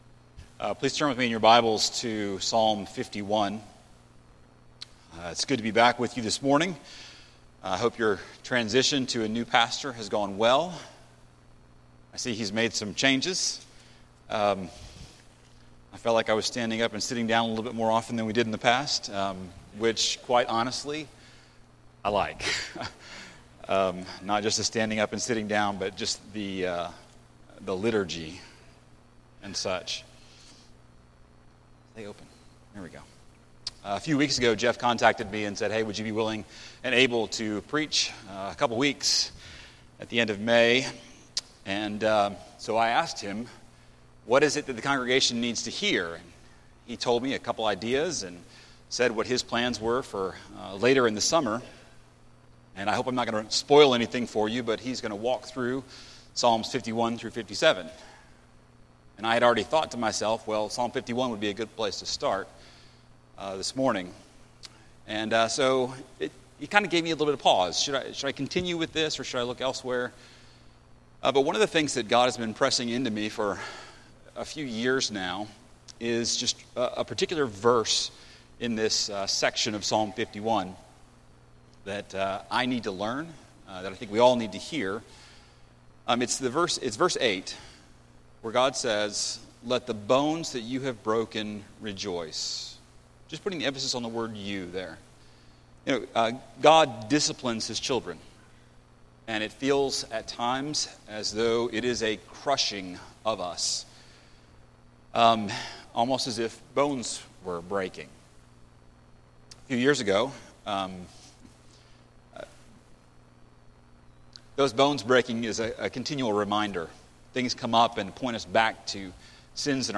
Sermon on Psalm 51:7-12 from May 31